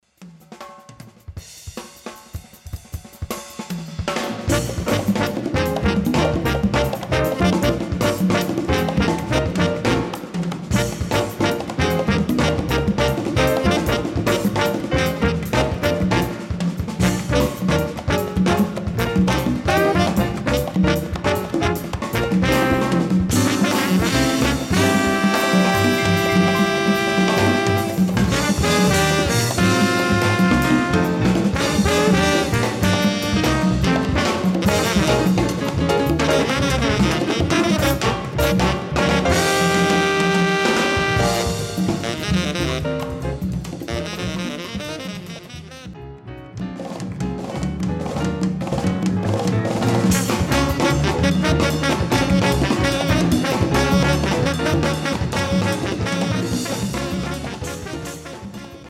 Category: combo (septet)
Style: mambo
Solos: open